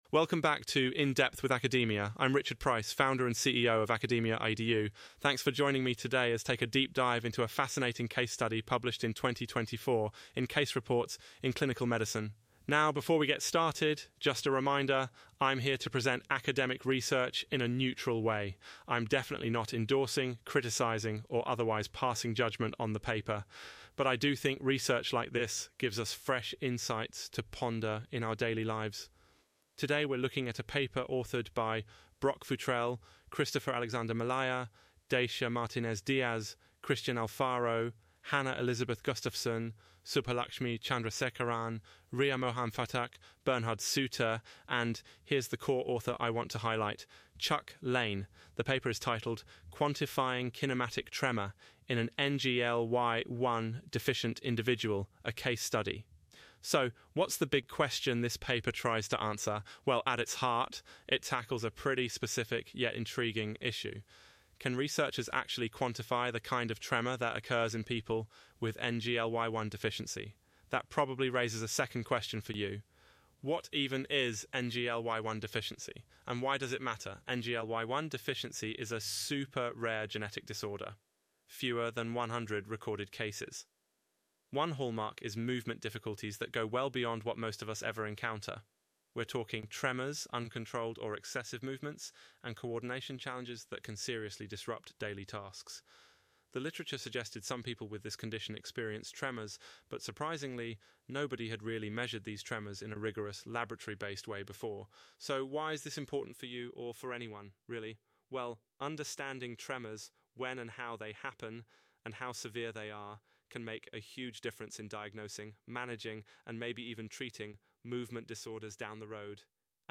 Automated Audio Summary